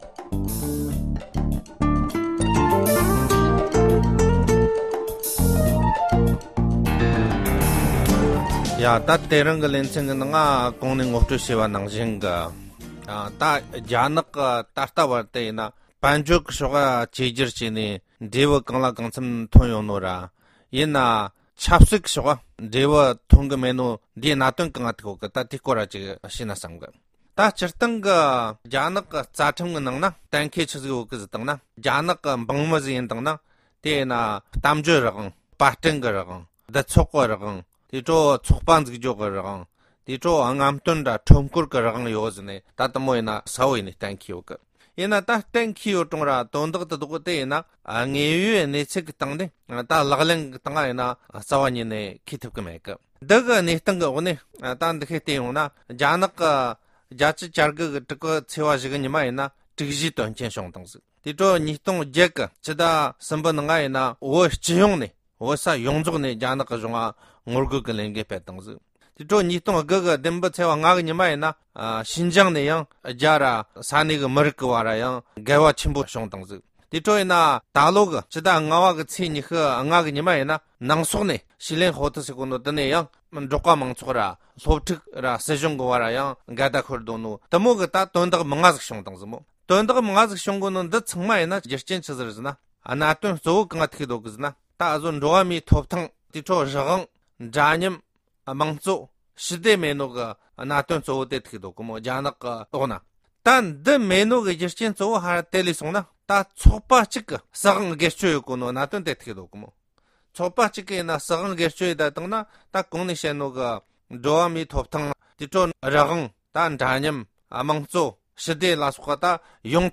དཔྱད་གཏམ་སྤེལ་བར་ཉན་རོགས་གནོངས༎